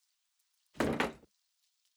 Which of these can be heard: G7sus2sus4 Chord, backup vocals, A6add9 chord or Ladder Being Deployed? Ladder Being Deployed